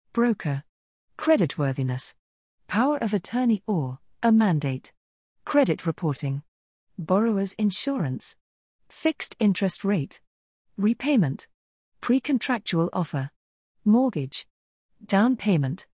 English vocabulary !
Entraînez-vous à prononcer ces mots en anglais. Cliquez sur les icônes fille et garçon pour écouter la prononciation.